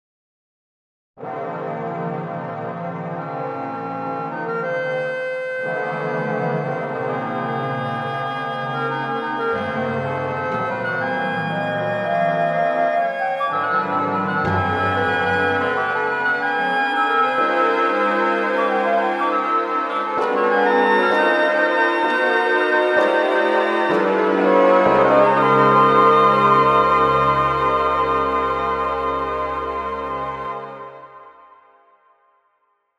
響きは「すっきり・あいまい」という感じです。